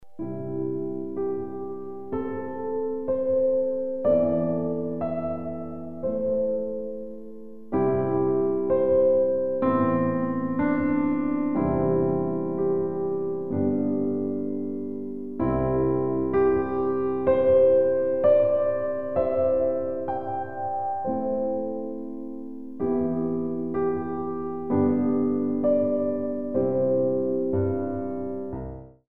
All piano CD for Pre- Ballet classes.